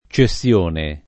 cessione [ © e SSL1 ne ] s. f.